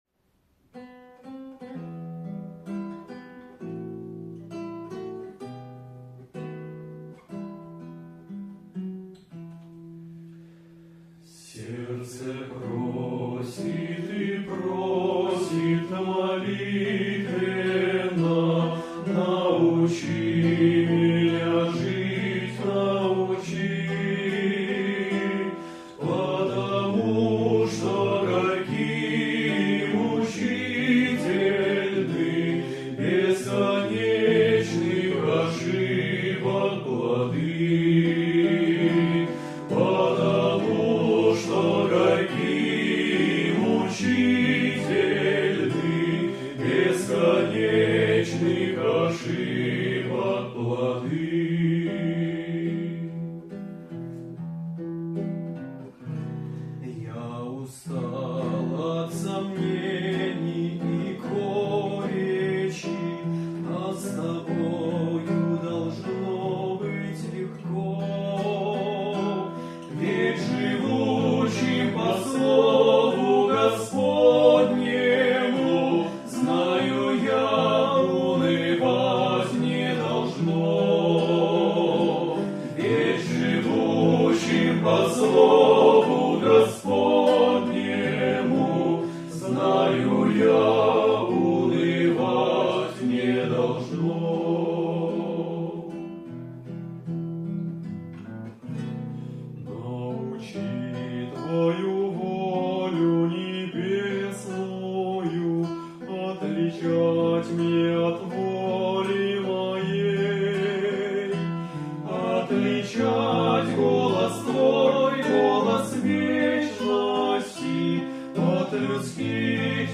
92 просмотра 144 прослушивания 5 скачиваний BPM: 186